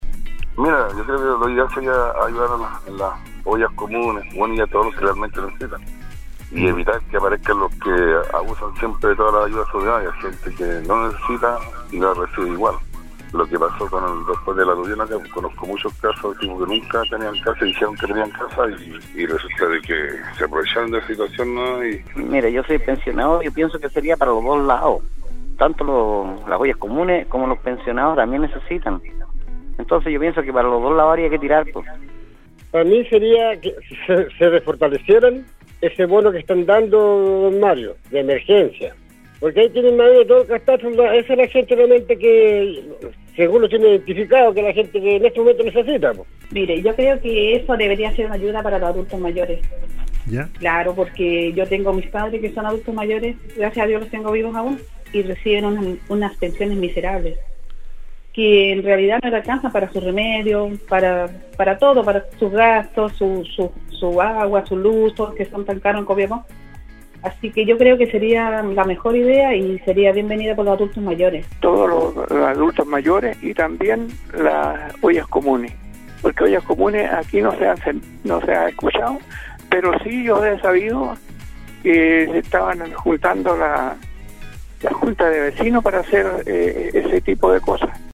La mañana de este lunes, en el programa Al día de Nostálgica, el Foro de Opinión estuvo centrado en la confirmación de la campaña solidaria Chile Ayuda a Chile.